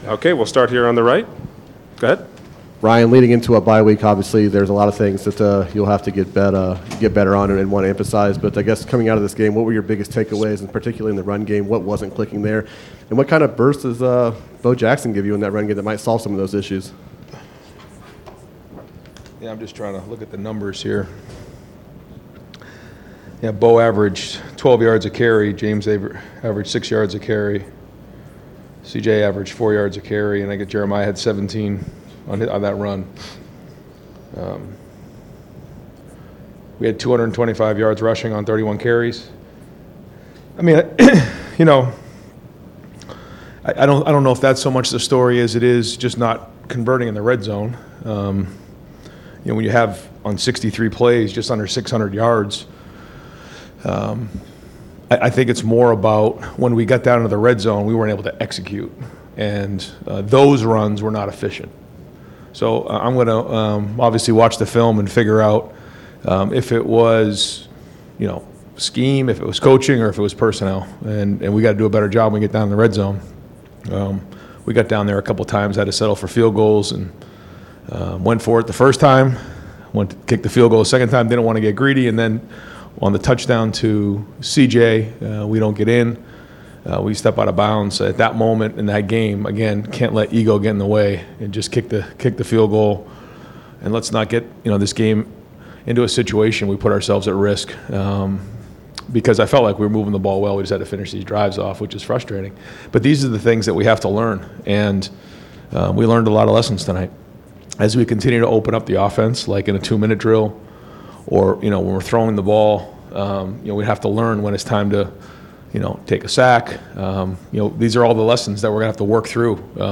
#1 Ohio State wins comfortably over a tough Ohio team but Ryan Day says their are areas to get better; Full Post-game Press Conference - Mega Sports News